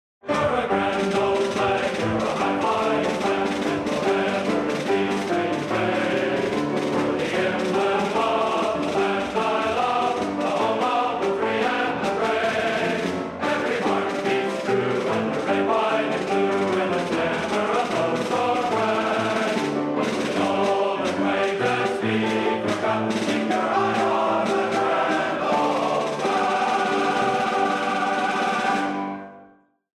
America, Our Heritage - BNC Plainsmen Spring Concert 1967